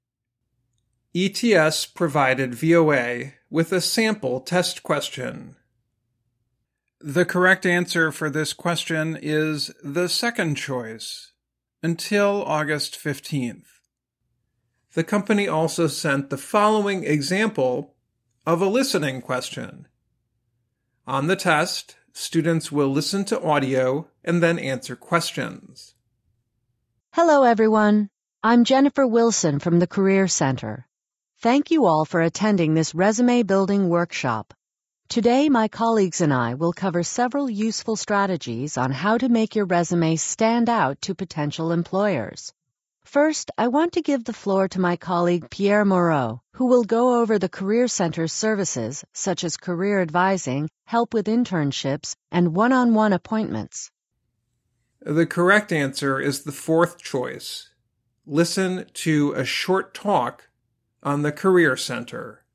The company also sent the following example of a listening question.